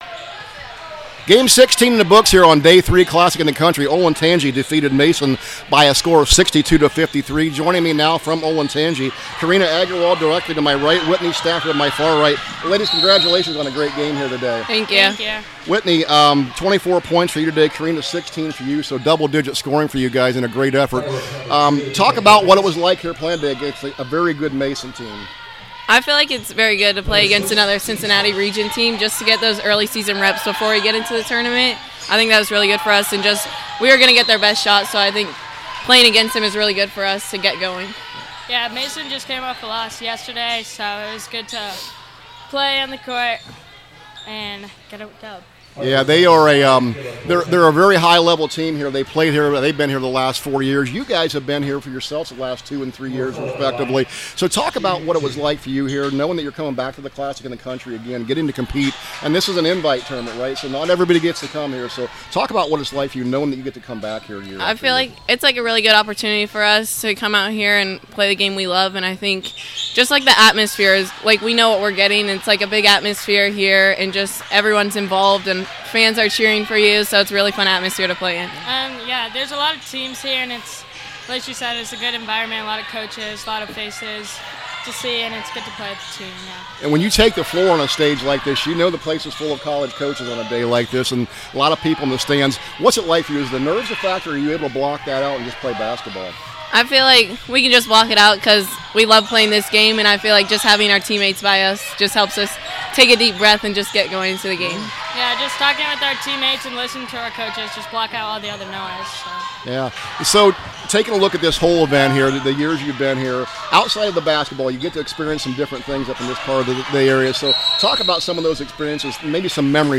CLASSIC 2026 – OLENTANGY PLAYERS INTERVIEW